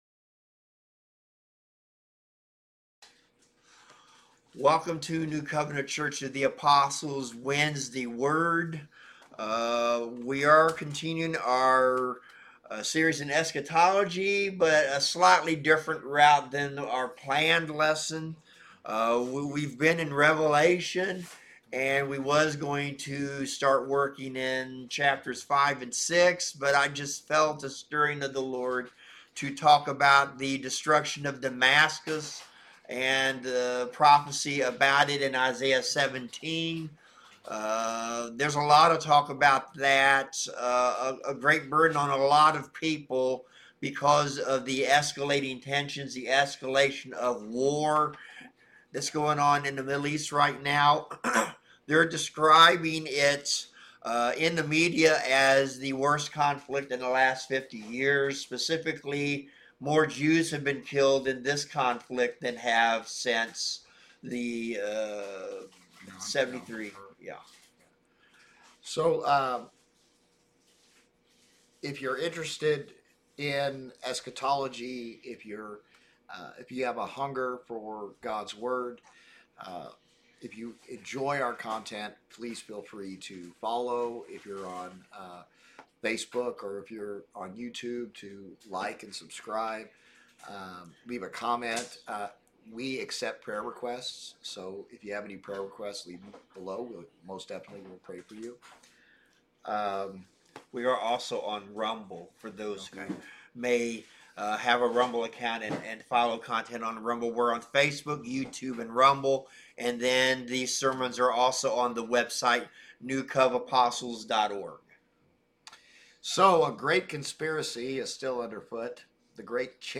Passage: Isaiah 17 Service Type: Wednesday Word Bible Study